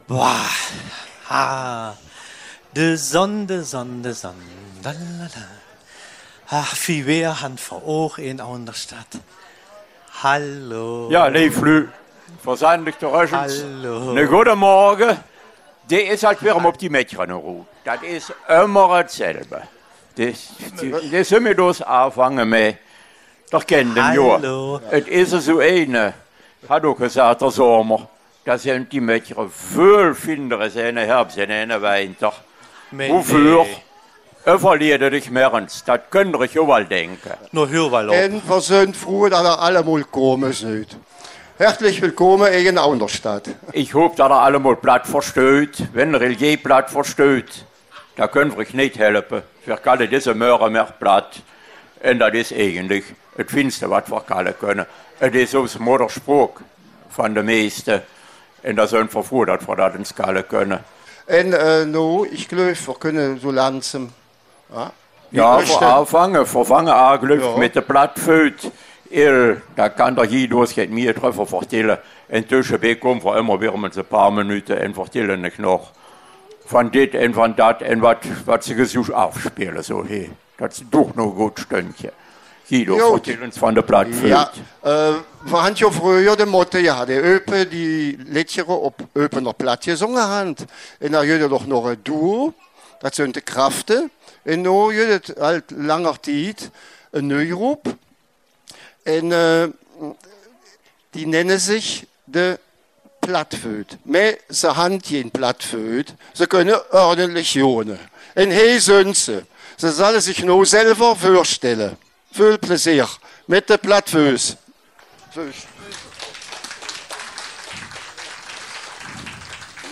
Den musikalischen Part übernimmt die Band ''Plattvoet''. Sie präsentiert an verschiedenen Instrumenten und am Mikrofon Lieder auf Öüpener Platt.